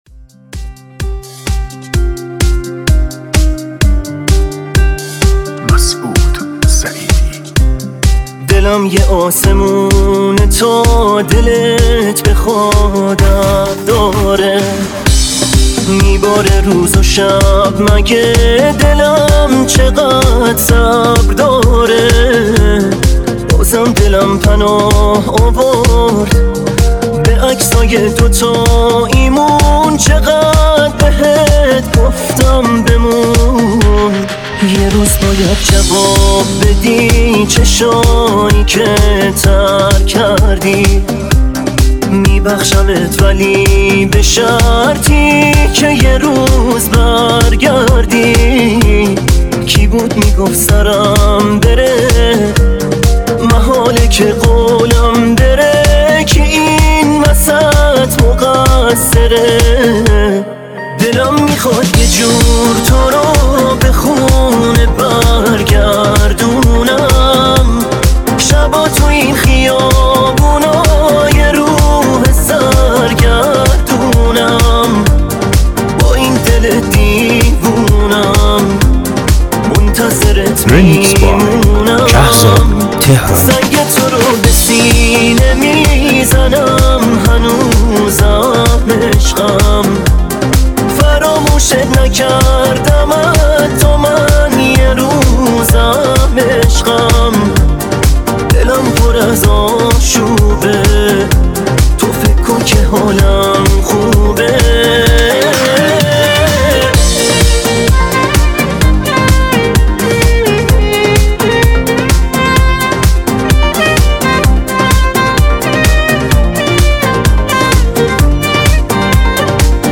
اثری احساسی و خاطره‌انگیز